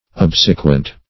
Search Result for " obsequent" : The Collaborative International Dictionary of English v.0.48: Obsequent \Ob"se*quent\, a. [L. obsequens, p. pr. of obsequi; ob (see Ob- ) + sequi.